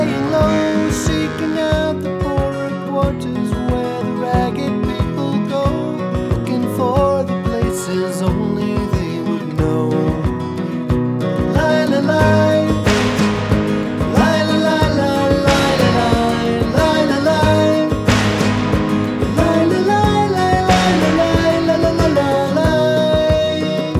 With Harmony Pop (1960s) 5:10 Buy £1.50